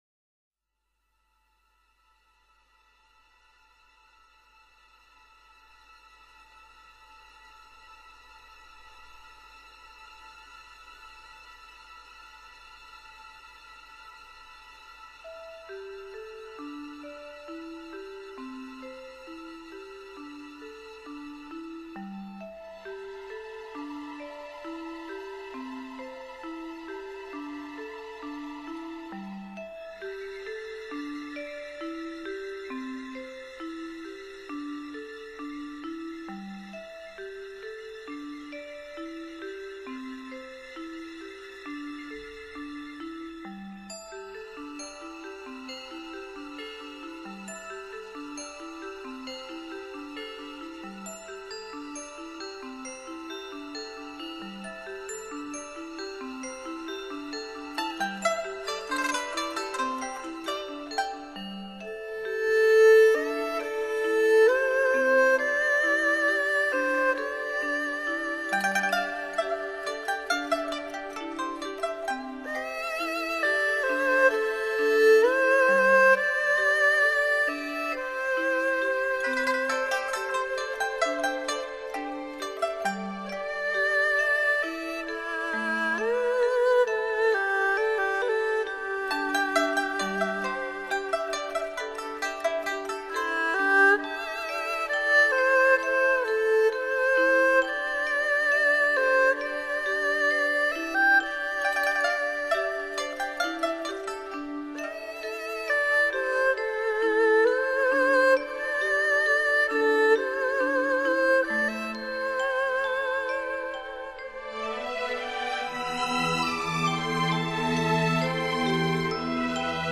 心灵音乐馆
笛子
琵琶
古筝